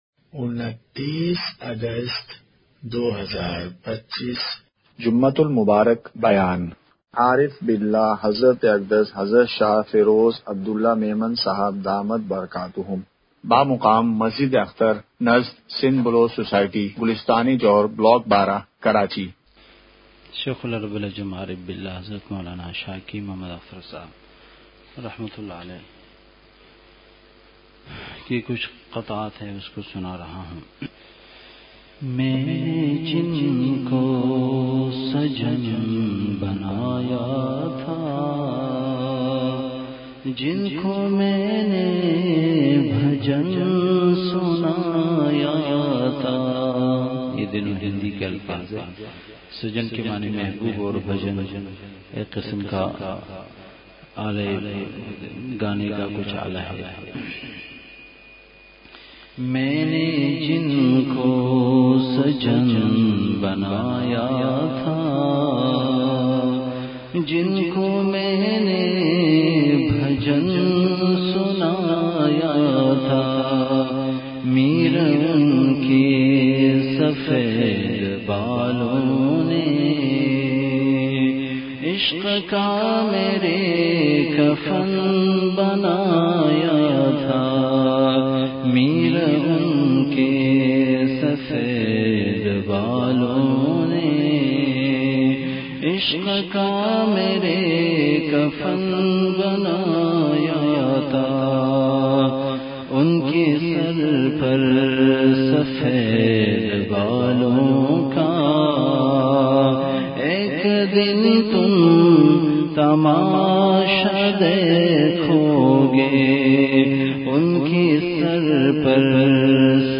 جمعہ بیان ۲۹ / اگست ۲۵ء:فضائل توبہ و استغفار !
مقام:مسجد اختر نزد سندھ بلوچ سوسائٹی گلستانِ جوہر کراچی